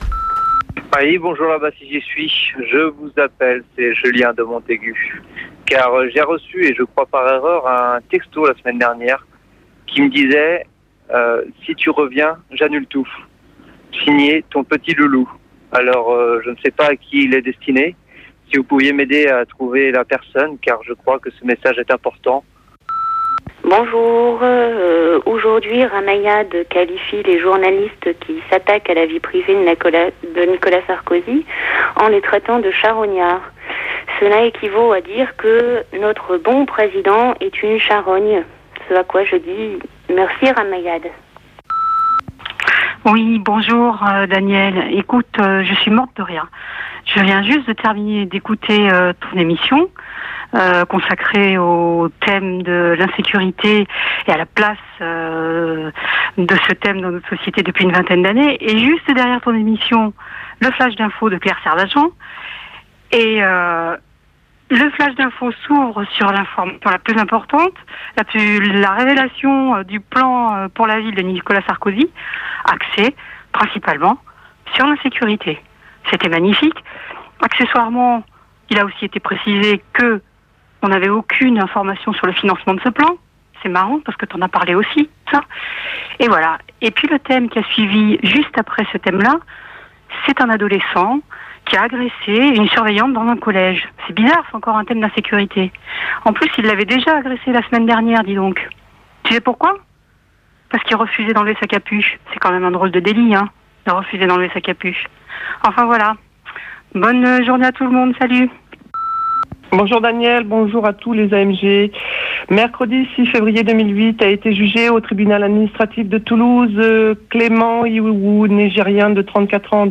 Entretien avec Daniel Bensaïd autour de son dernier ouvrage.
L’émission radiophonique